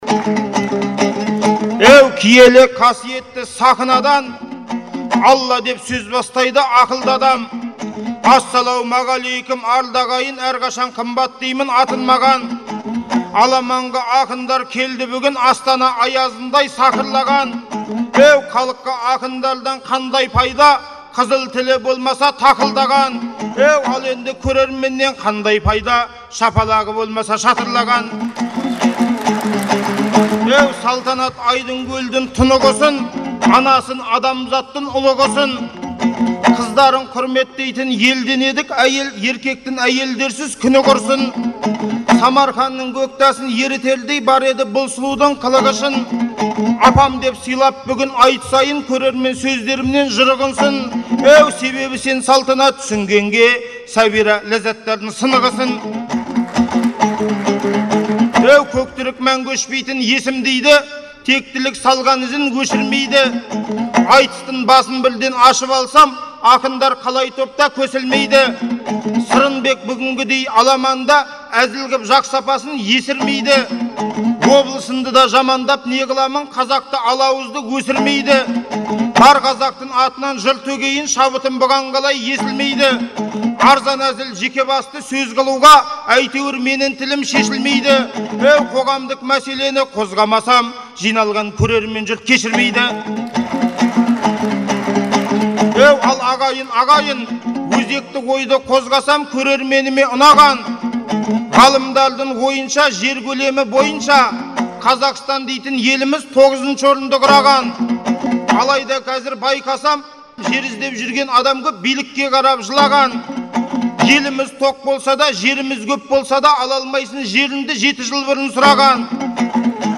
айтысы